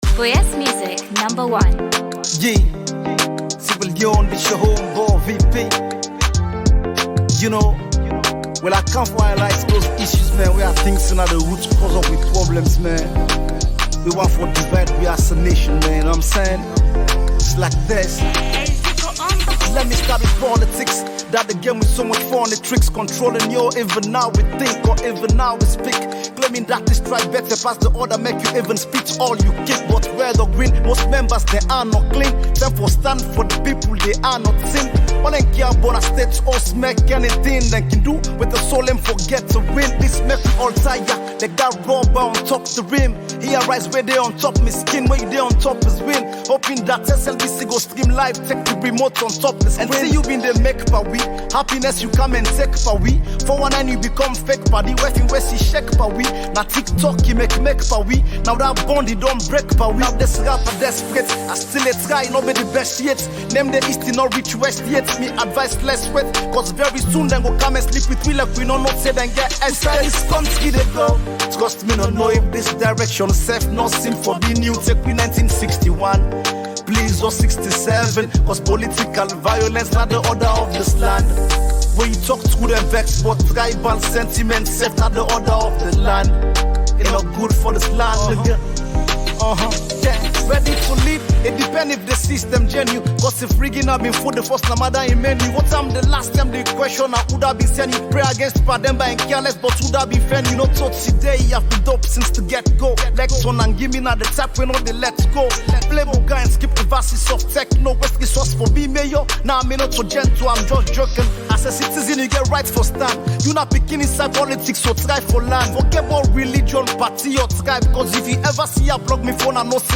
Hard-core rapper